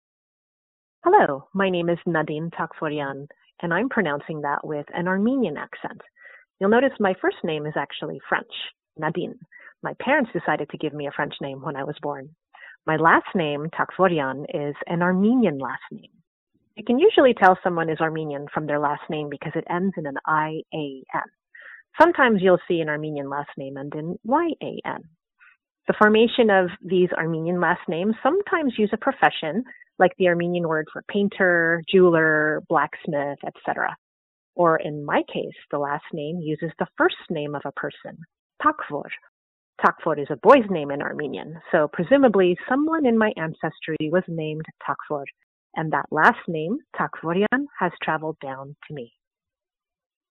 Audio Name Pronunciation